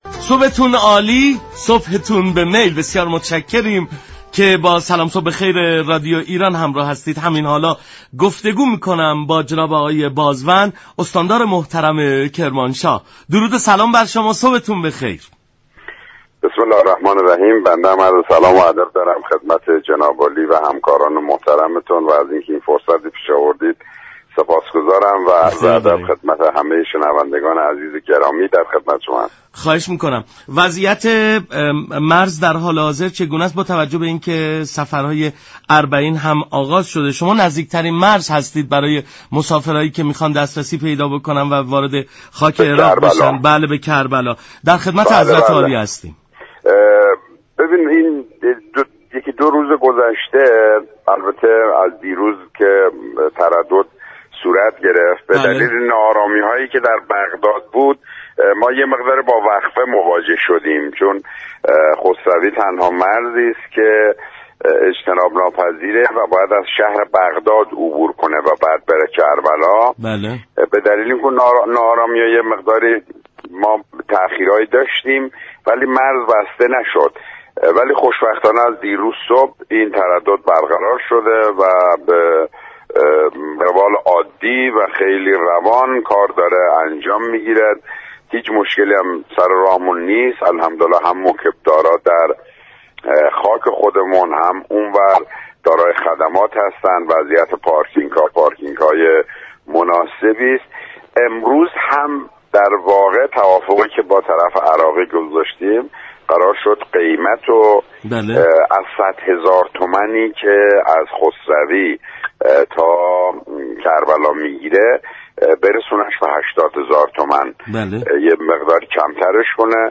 استاندار كرمانشاه در گفت و گو با برنامه سلام صبح بخیر رادیو ایران گفت: هموطنانی كه قصد سفر به كربلا و نجف اشرف را دارند توصیه می شود ترددهای خود را تا زمان تعیین شده ( ساعت 16 به وفت عراق) انجام دهند.